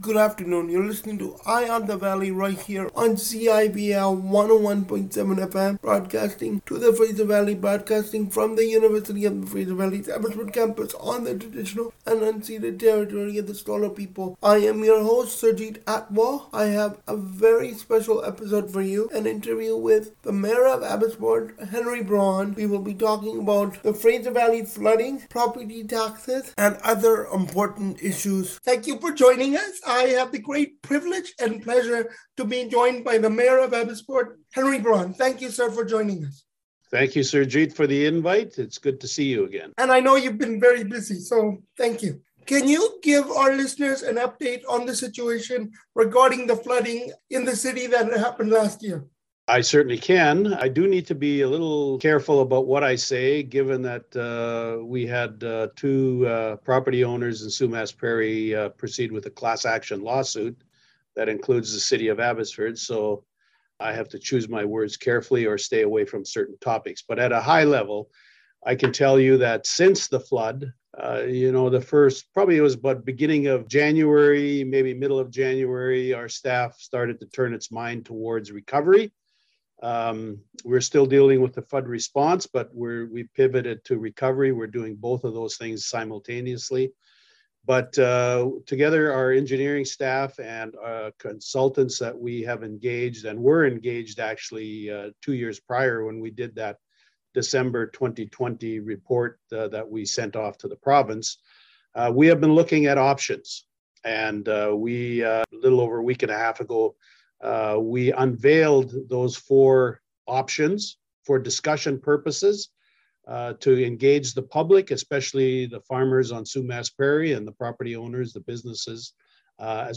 Abbotsford-mayor-interview-part-1.mp3